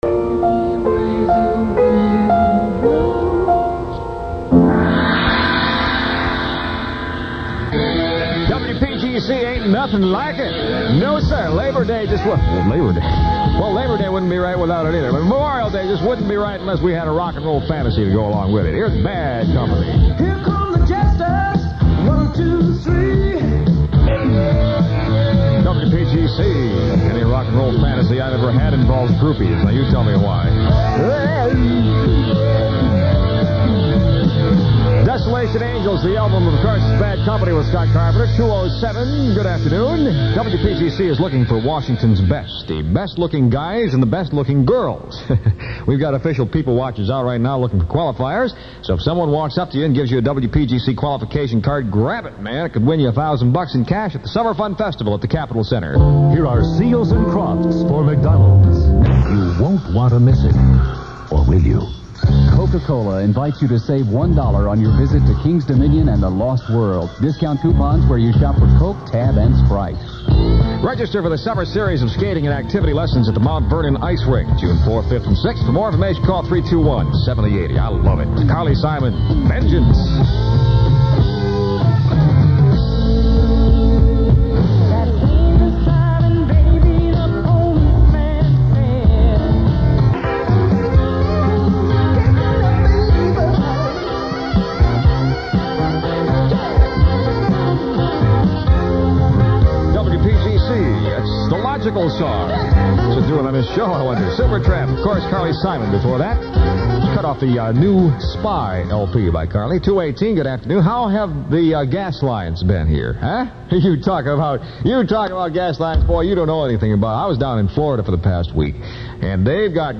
Mechanically, the station had introduced cold segues between two songs without any identifying element in between them the year before. The 'Positron' jingles from JAM from the year before were noticeably absent by Summer 1979 outside of the morning show.